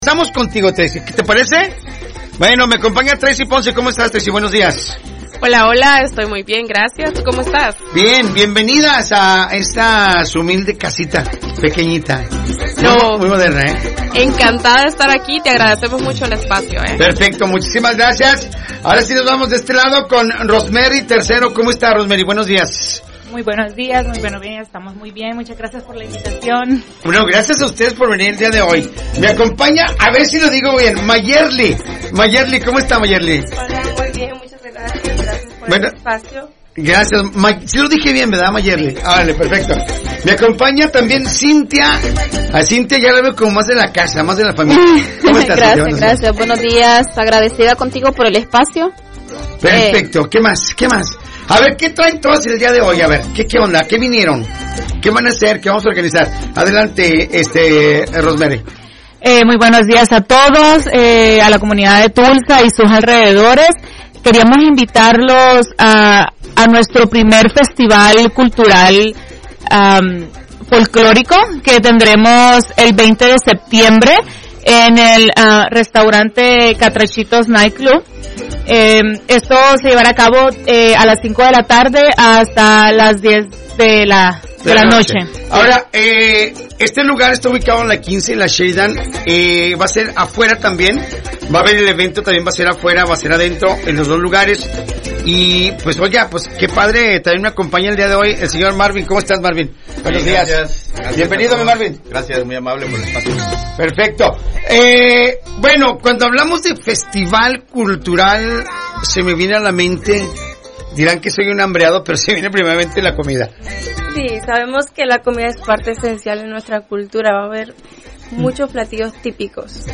Entrevista-CulturasTulsa-09Septiembre25.mp3